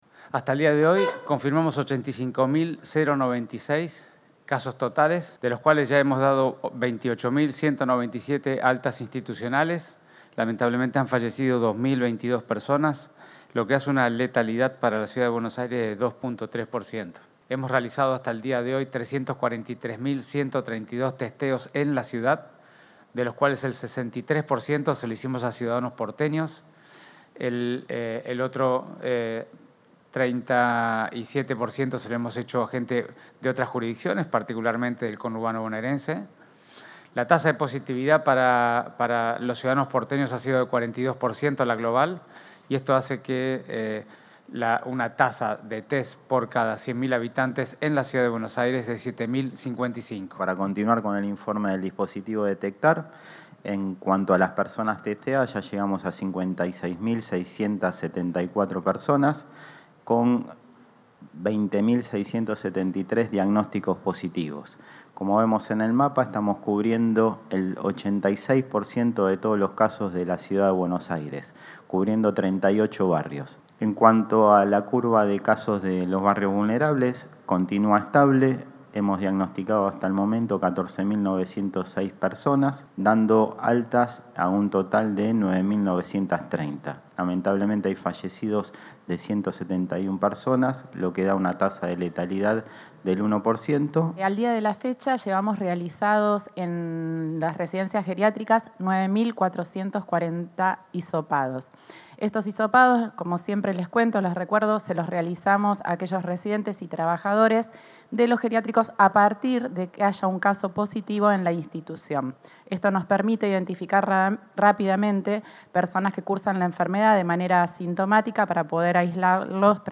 El ministro de Salud porteño, Fernán Quirós; el subsecretario de Atención Primaria, Gabriel Battistella, y la directora general de Planificación Operativa, Paula Zingoni, brindaron hoy detalles de la situación sanitaria de la Ciudad en relación a la curva de contagios y su evolución, en el marco del aislamiento social, preventivo y obligatorio por el coronavirus.